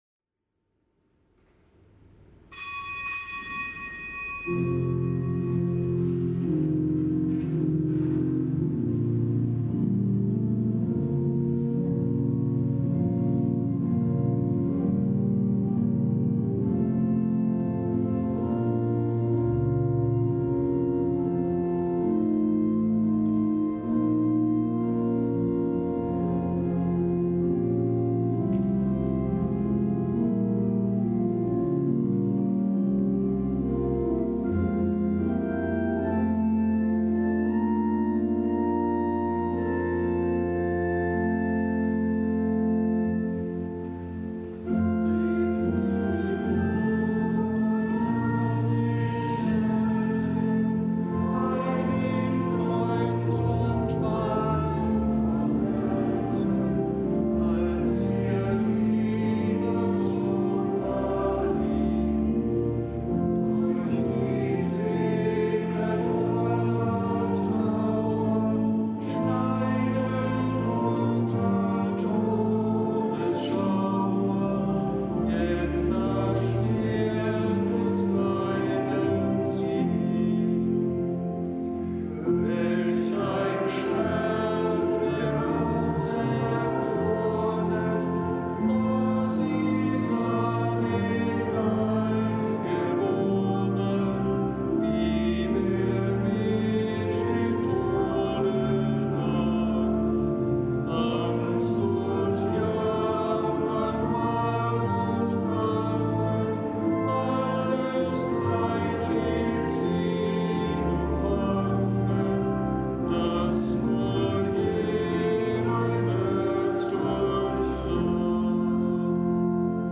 Kapitelsmesse am Gedenktag Gedächtnis der Schmerzen Mariens
Kapitelsmesse aus dem Kölner Dom am Gedenktag Gedächtnis der Schmerzen Mariens.